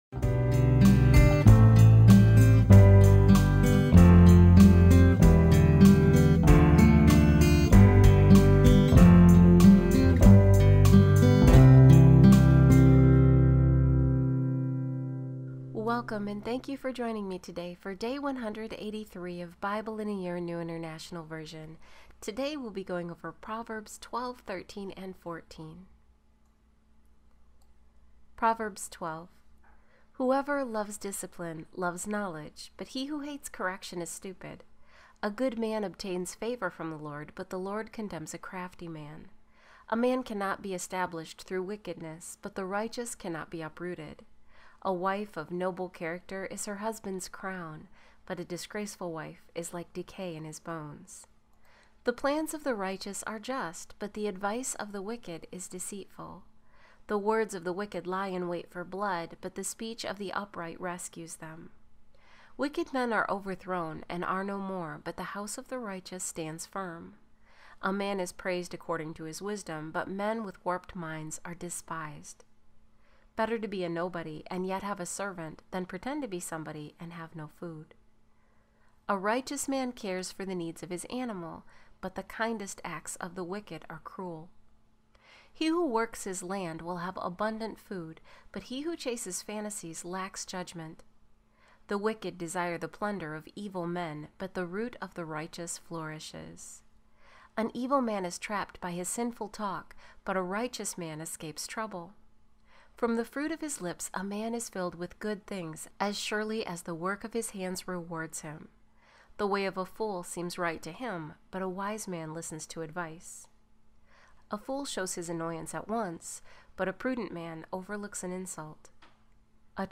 The 183rd of daily biblical readings.